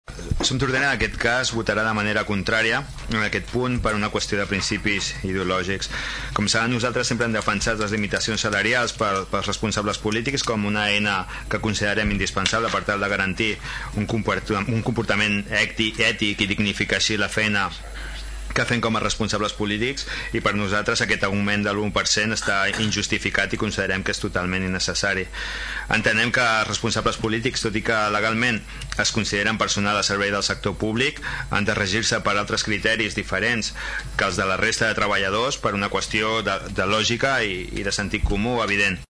IMGP2097El passat Ple de l’Ajuntament de Tordera, va aprovar l’augment del sou de l’1% dels representants de la corporació local.
Per part del grup SOM Tordera, Salvador Giralt justifica el seu vot contrari per motius ideològics.